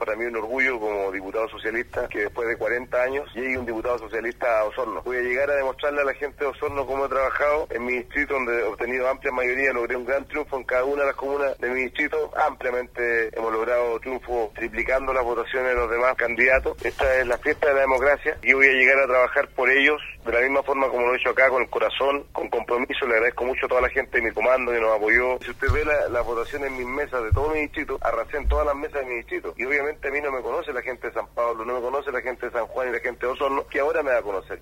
Al respecto, Fidel Espinoza comentó orgulloso que después de 40 años llegue un diputado socialista a Osorno, añadiendo que trabajará por el Distrito con corazón y compromiso.